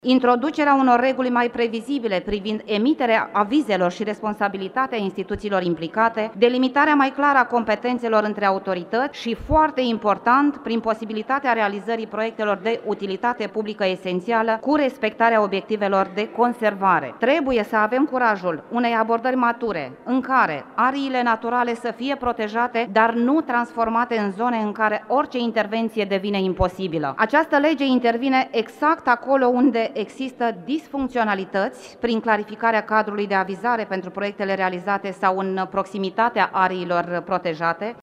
Senatoarea PSD Doina Federovici a motivat în plen că această modificare a legii ar permite ca anumite proiecte și investiții să nu mai întârzie în așteptarea avizelor de mediu.
Senatoarea PSD Doina Federovici: „Această lege intervine acolo unde există disfuncționalități”